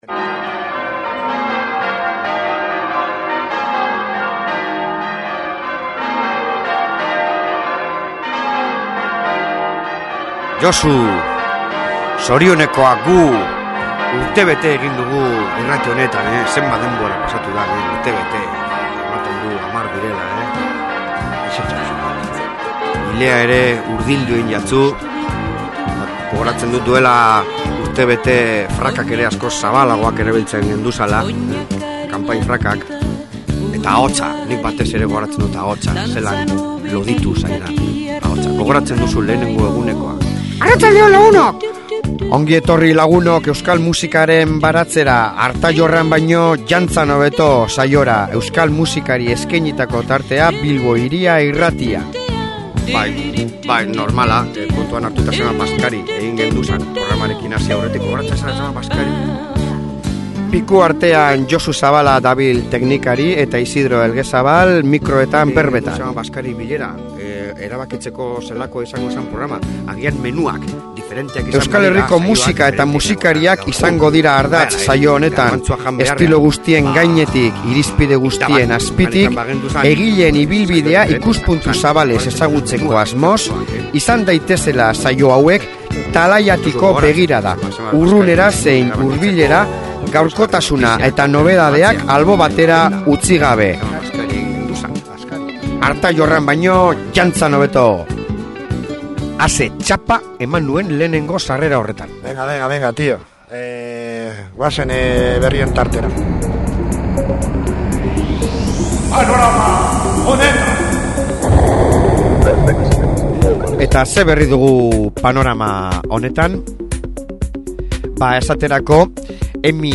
Urtebetea egin dugu! 2009ko otsailaren 19an hasi ginen eta hainbat izan dira urtean zehar egindako hankasartzeak… akatsez jositako saioa gaur, antxoak, gallitoak ,barreak, errata eskandalagarriak…eta abar!